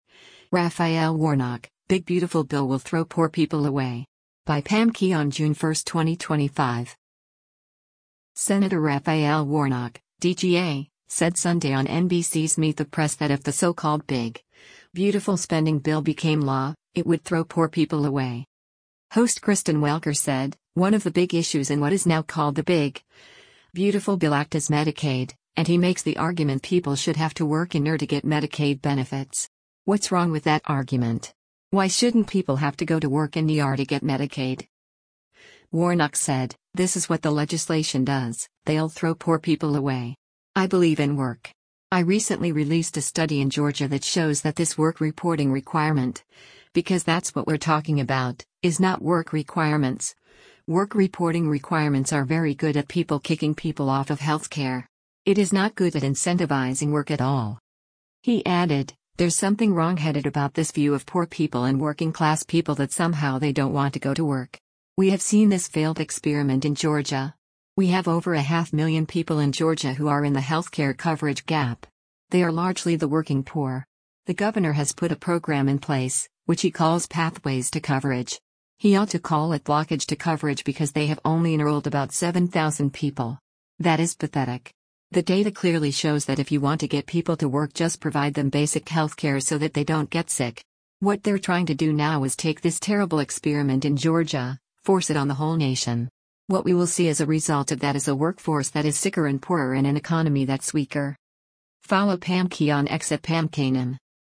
Senator Raphael Warnock (D-GA) said Sunday on NBC’s “Meet the Press” that if the so-called “big, beautiful” spending bill became law, it would “throw poor people away.”